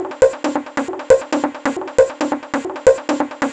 • techno synth sequence 136 4.wav
techno_synth_sequence_136_4_CGw.wav